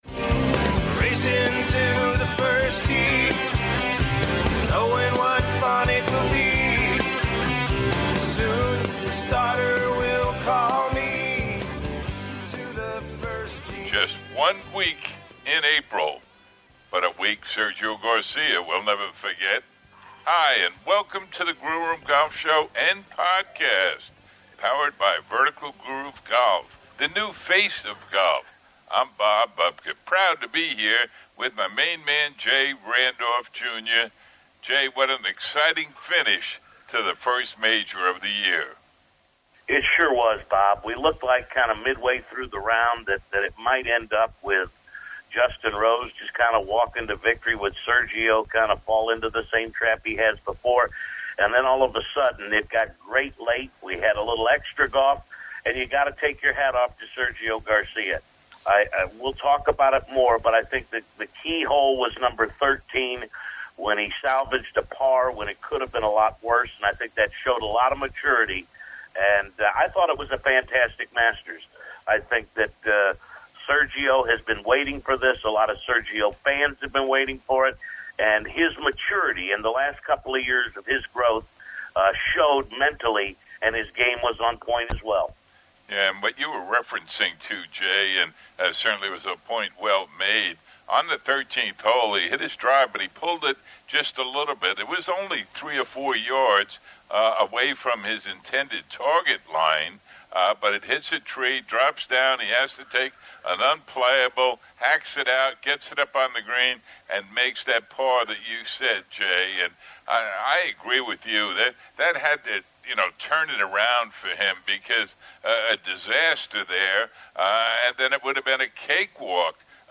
John Daly talks about his Vertical Groove Driver and the success he's having with it.